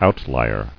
[out·li·er]